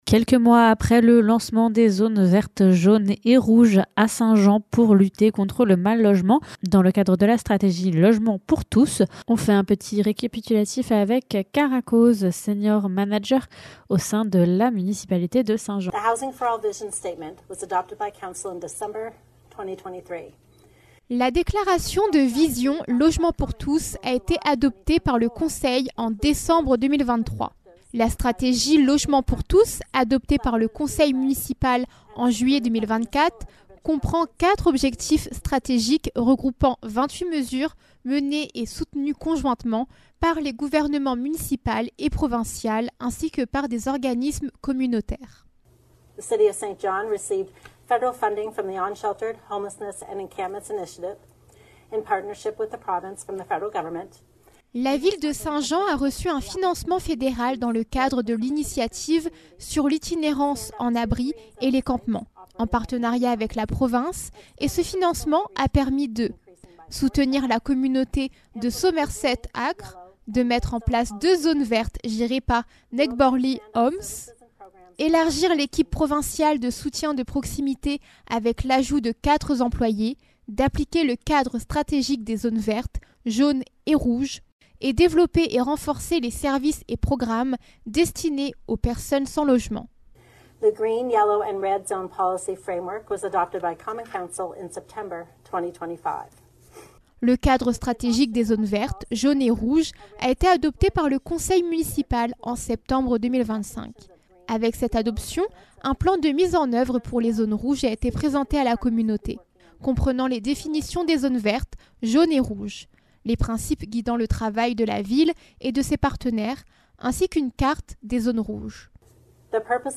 Une entrevue